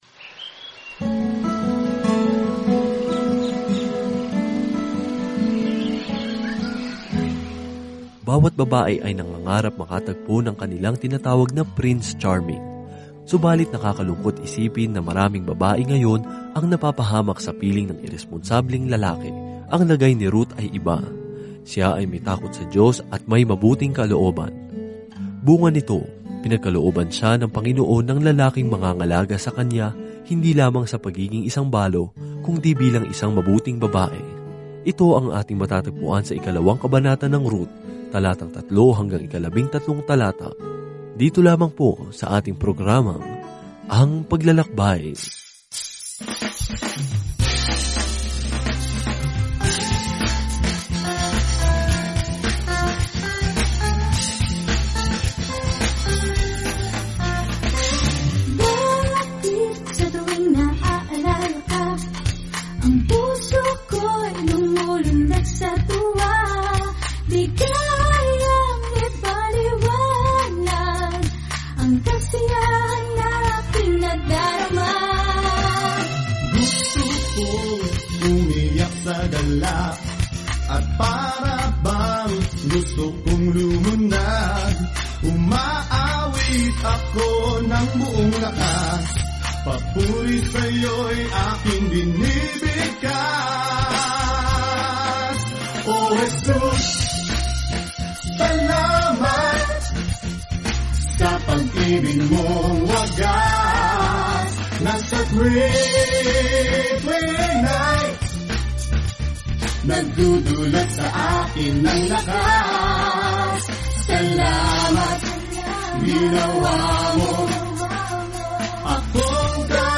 Banal na Kasulatan Ruth 2:1-3-13 Araw 2 Umpisahan ang Gabay na Ito Araw 4 Tungkol sa Gabay na ito Si Ruth, isang kuwento ng pag-ibig na sumasalamin sa pag-ibig ng Diyos sa atin, ay naglalarawan ng mahabang pananaw sa kasaysayan–kabilang ang kuwento ni haring David... at maging ang backstory ni Jesus. Araw-araw na paglalakbay kay Ruth habang nakikinig ka sa audio study at nagbabasa ng mga piling talata mula sa salita ng Diyos.